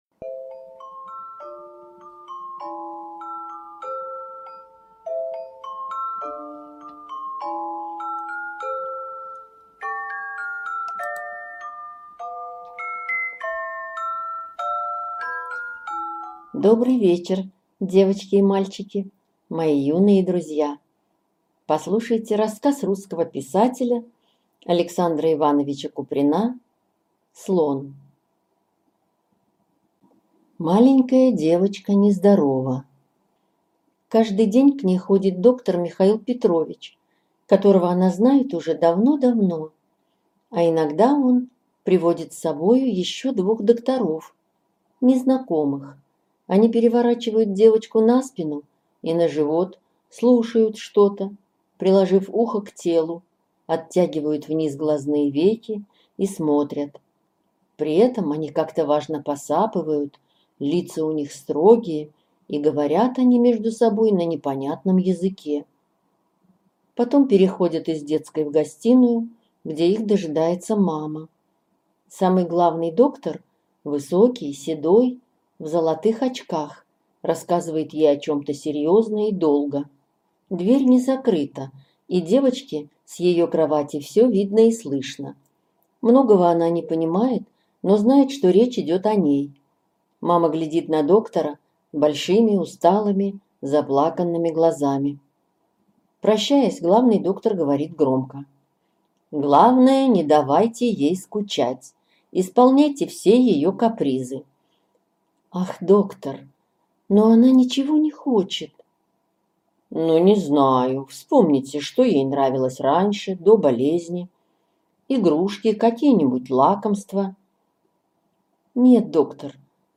Слон -аудио рассказ Куприна А.И. Рассказ про девочку, которая была нездорова и равнодушна к жизни.